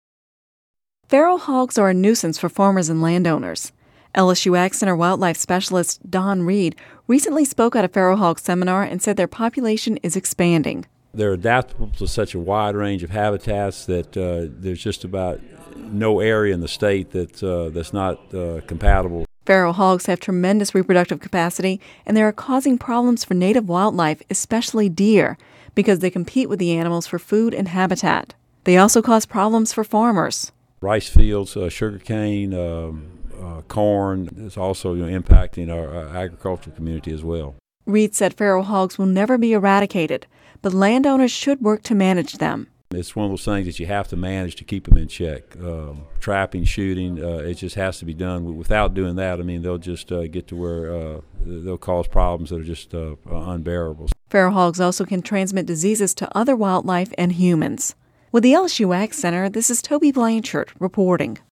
(Radio News 10/18/10) Feral hogs are a nuisance for farmers and landowners.